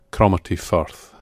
The Cromarty Firth (/ˈkrɒmərti/